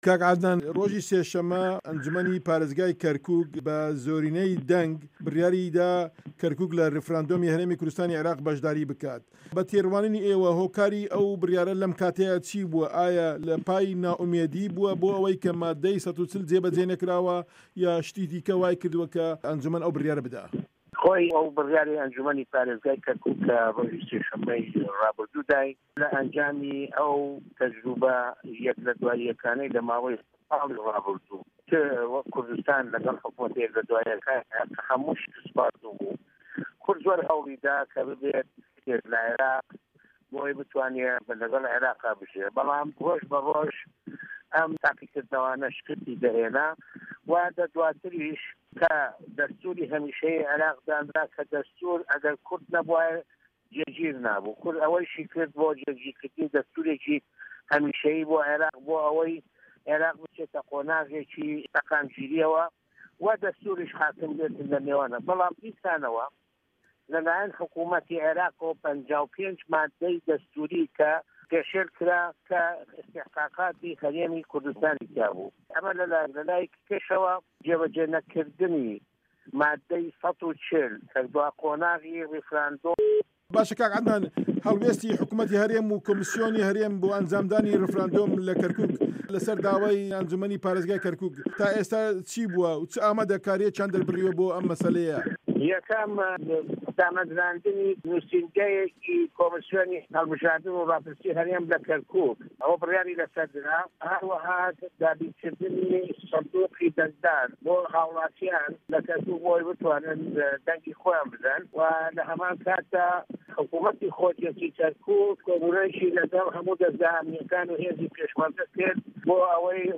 وتووێژ لەگەڵ عەدنان کەرکوکی